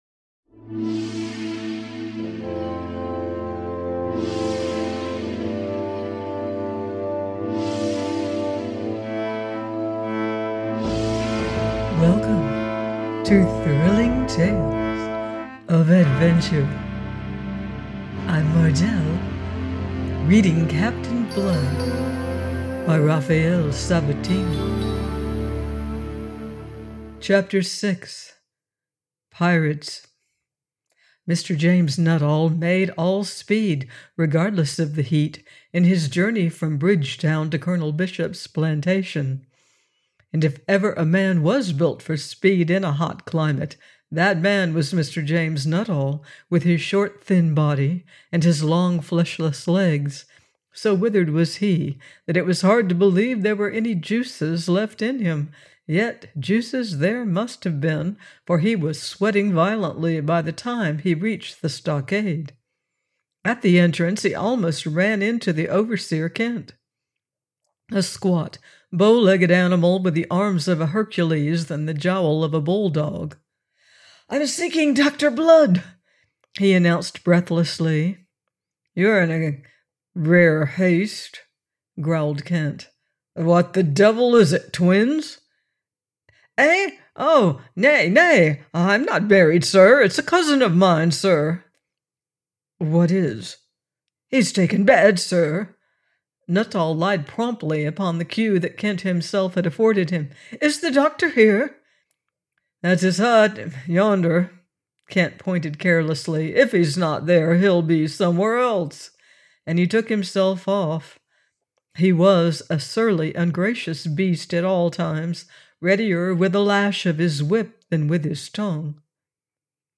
Captain Blood – by Raphael Sabatini - audiobook